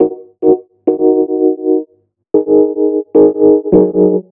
RI KEYS 2 -R.wav